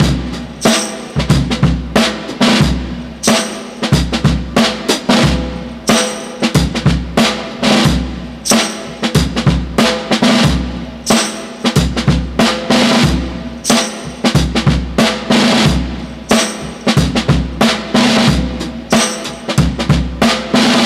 • 92 Bpm Drum Loop C Key.wav
Free breakbeat - kick tuned to the C note. Loudest frequency: 1704Hz
92-bpm-drum-loop-c-key-QQP.wav